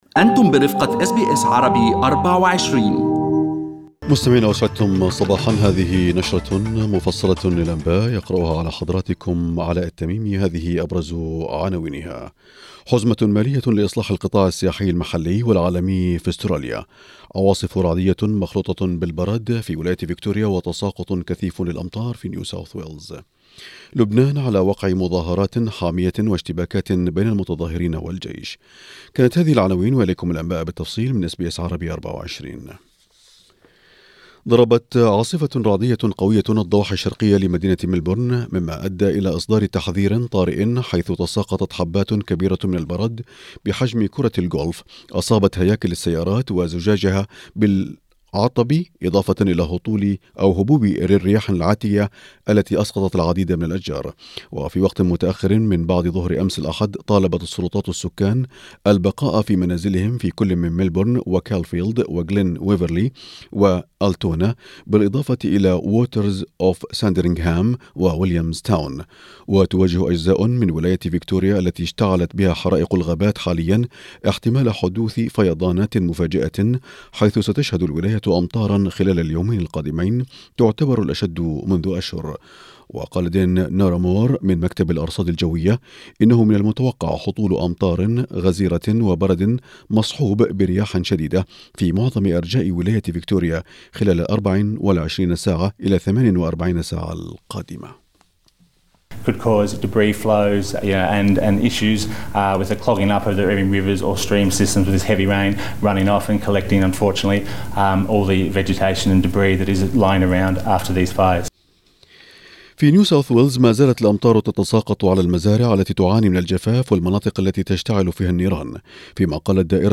Arabic News Bulletin Source: SBS Arabic24